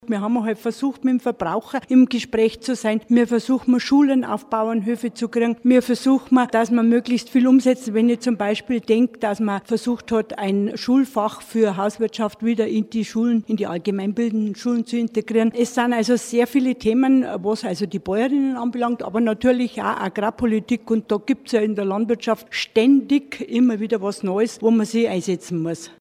Im Bayernwelle-Interview hat die stellvertretede Landrätin auf ihre Amtszeit als Kreibäuerin zurück geblickt: